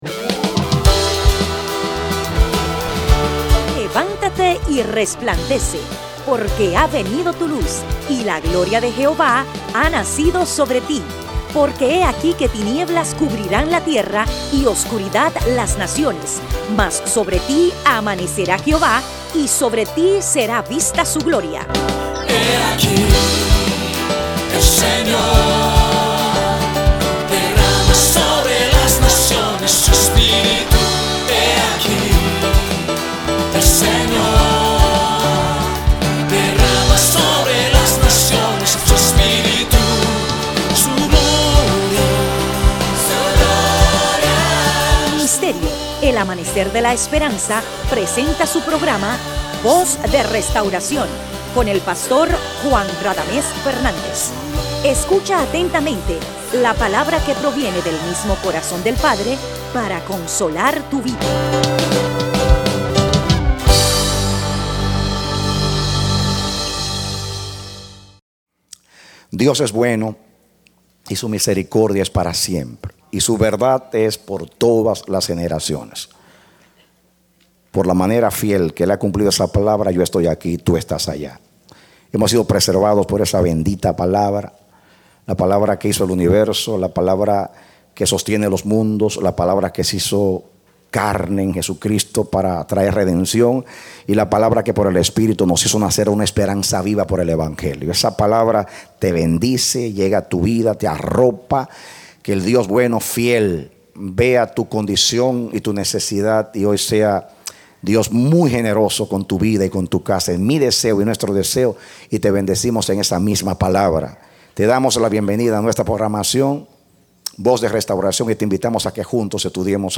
Parte A Predicado Febrero 17, 2013